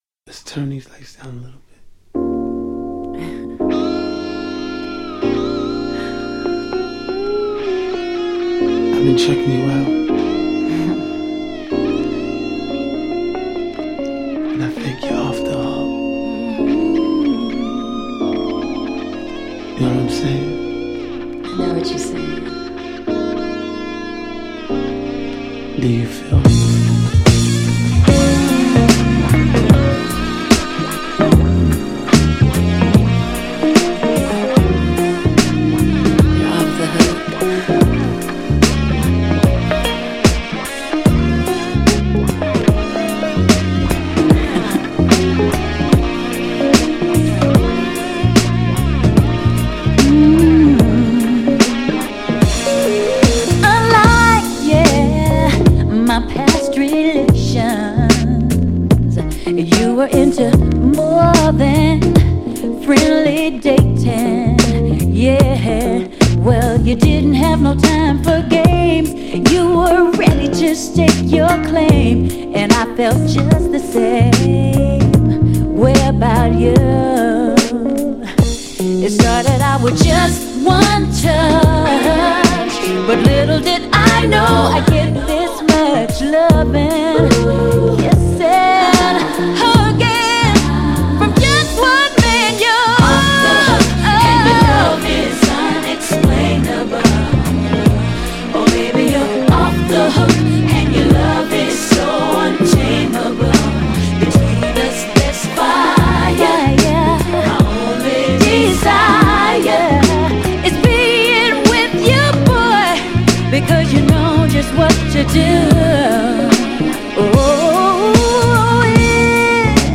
オリジナルのR&Bバージョン収録!! ２枚組US PROMO12
GENRE House
BPM 126〜130BPM